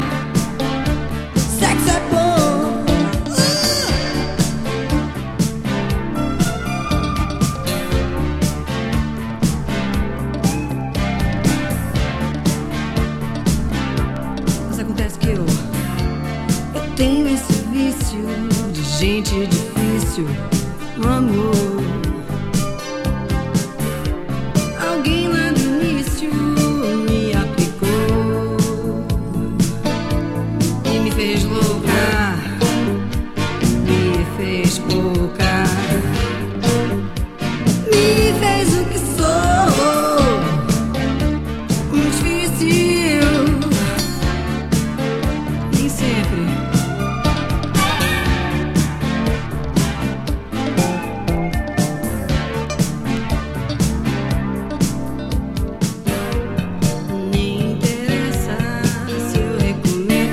ブラジルのロック世代シンガー’85年作。